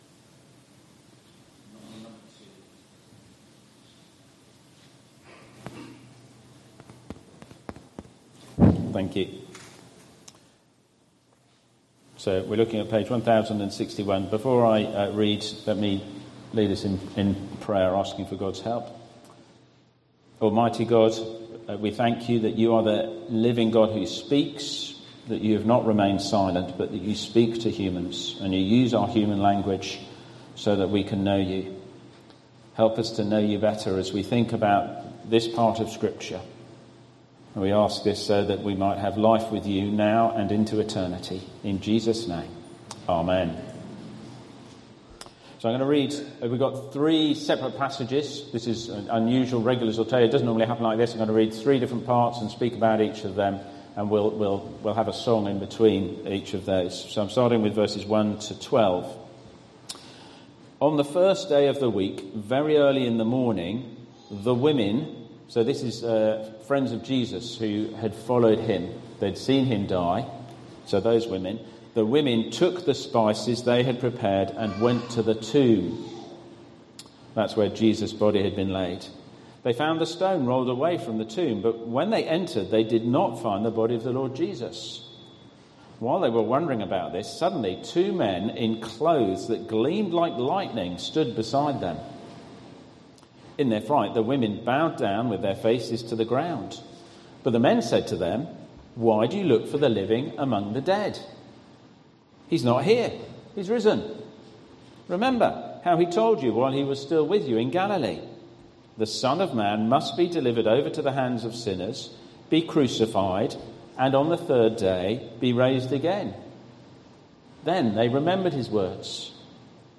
Easter Sunday Service 5th April 2026